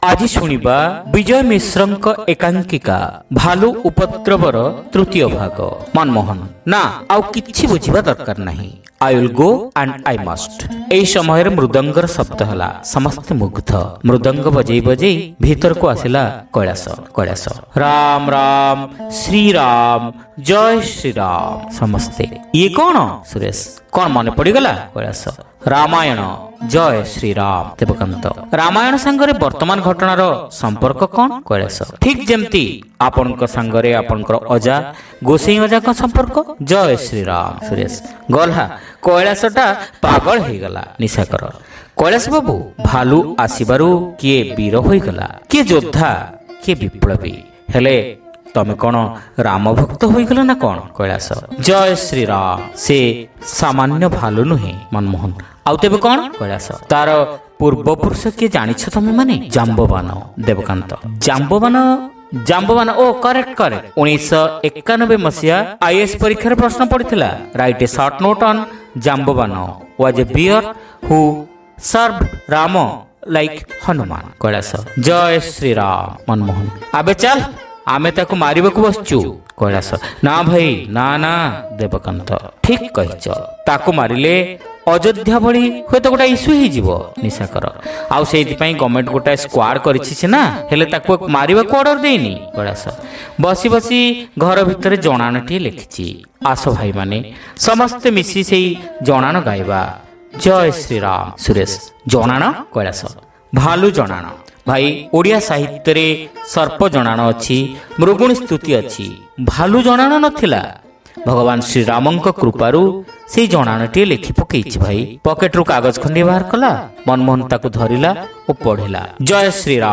Audio One act Play : Bhalu Upadraba (Part-3)